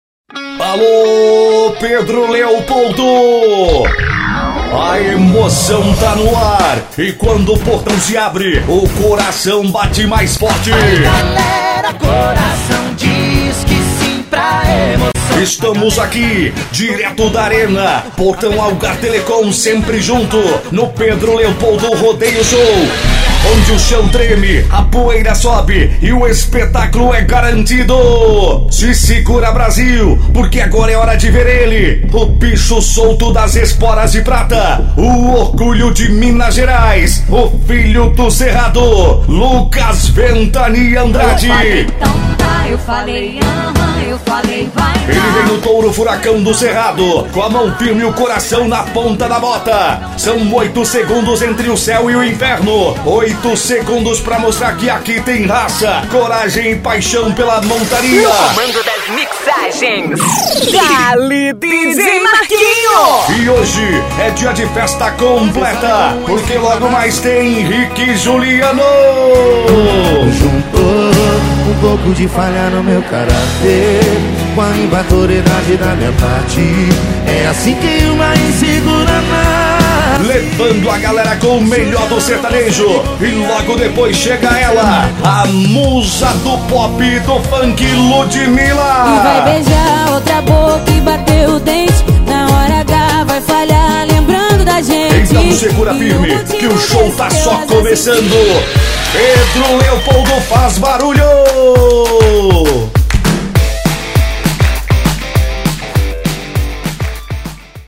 Caricata - Rodeio Arena - Vaquejada: